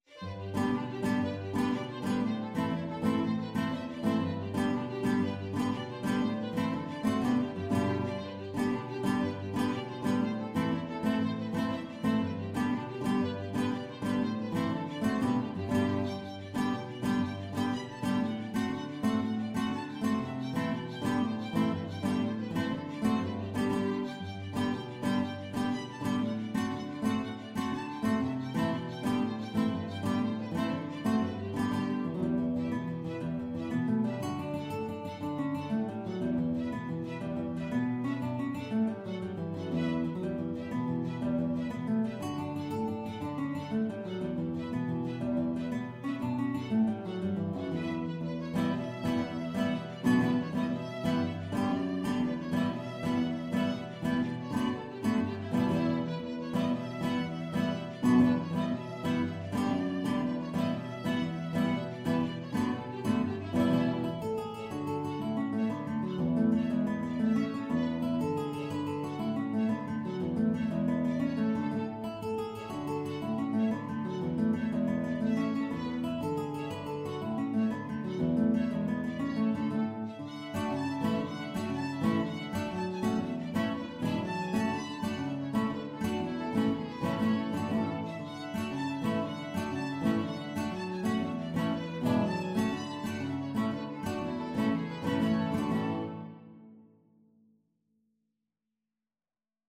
Moderato . = 120
6/8 (View more 6/8 Music)